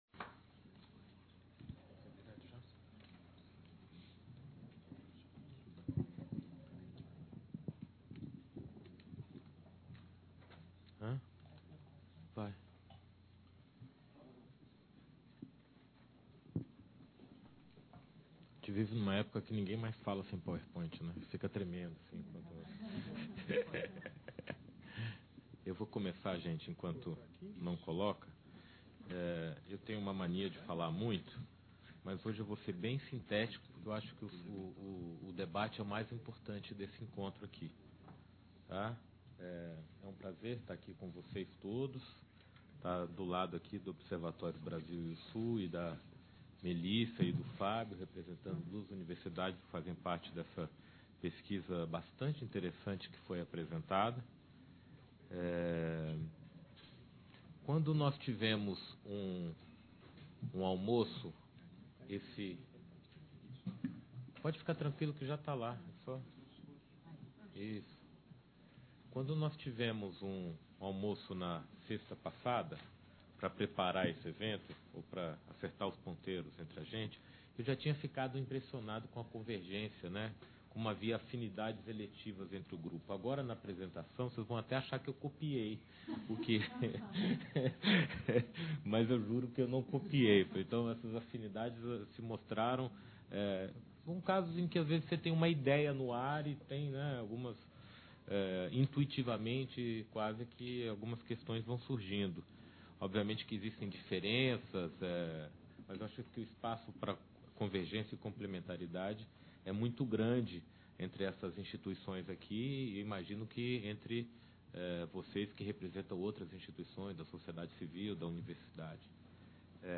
Exposição